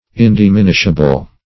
indiminishable - definition of indiminishable - synonyms, pronunciation, spelling from Free Dictionary
indiminishable.mp3